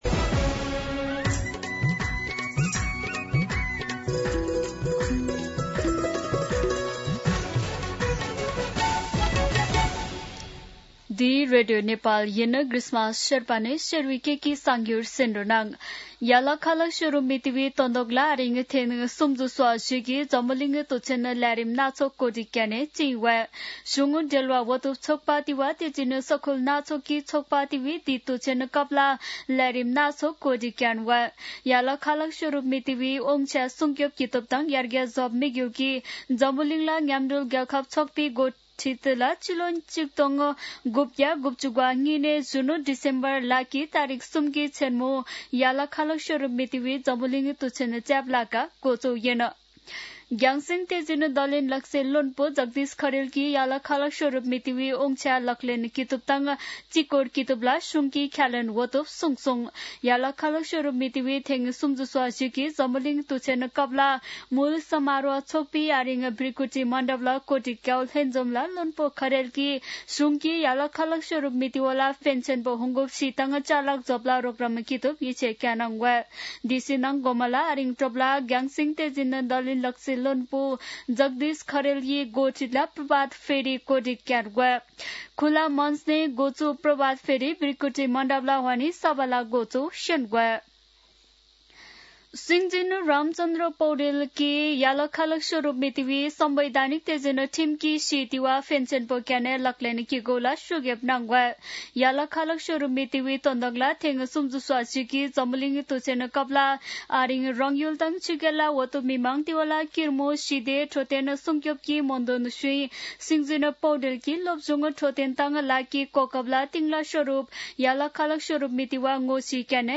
शेर्पा भाषाको समाचार : १७ मंसिर , २०८२